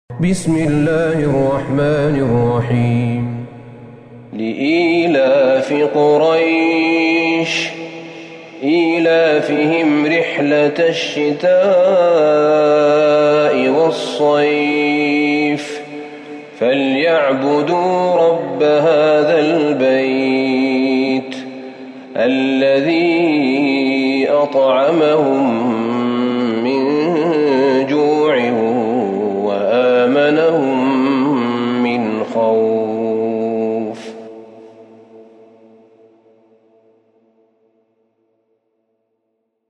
سورة قريش Surat Quraish > مصحف الشيخ أحمد بن طالب بن حميد من الحرم النبوي > المصحف - تلاوات الحرمين